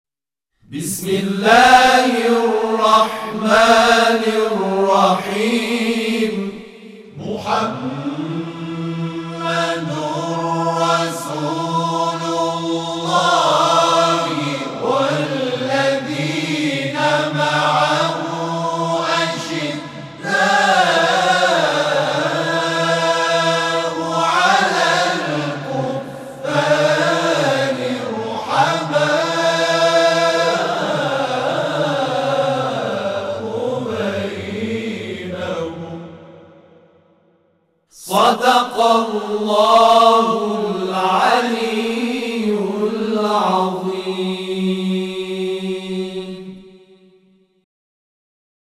صوت همخوانی آیه 29 سوره «فتح» از سوی گروه تواشیح «محمد رسول‌الله(ص)»